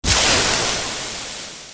grab_steam.wav